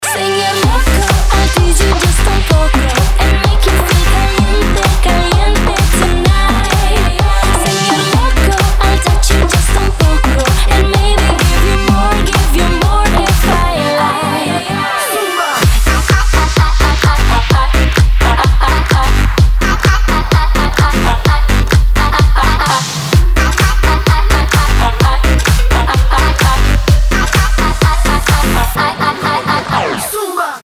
• Качество: 320, Stereo
поп
dance
Румынская танцевальная заводная Поп-музыка